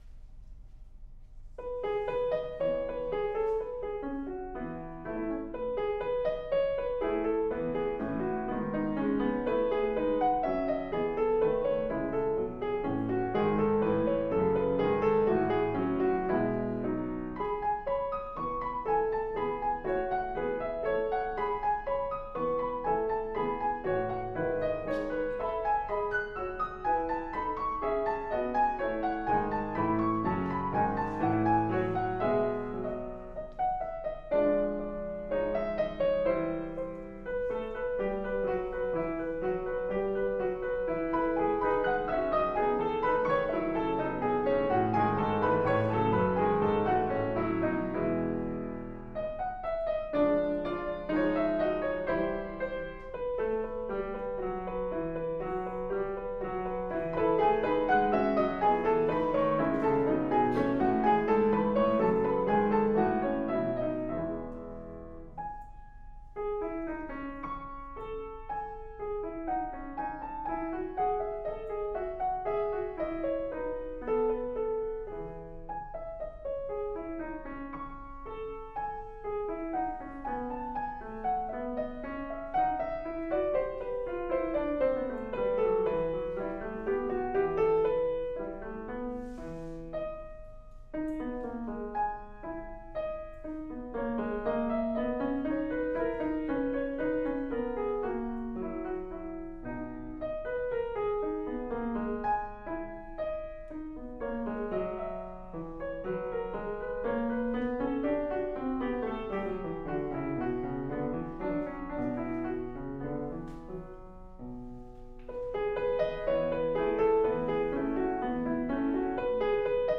Love Story for Two Pianos
Duet / 2010
Movement 2: Gavotte